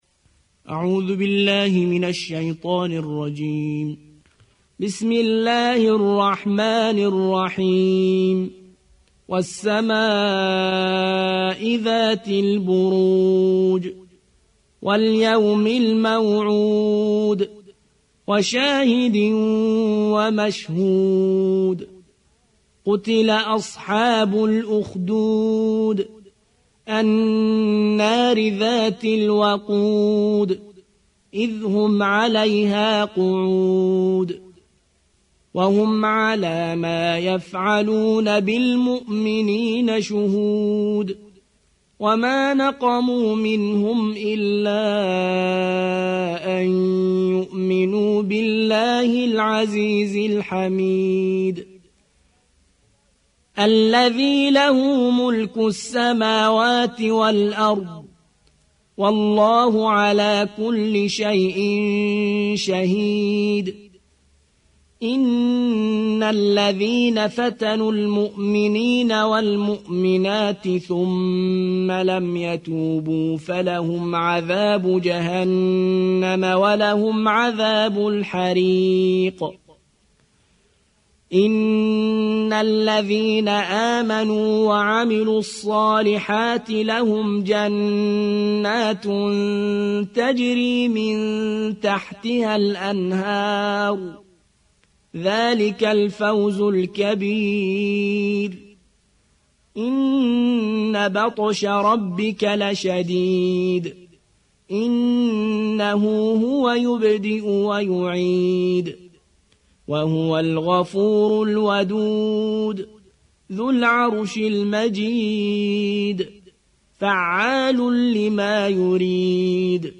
85. سورة البروج / القارئ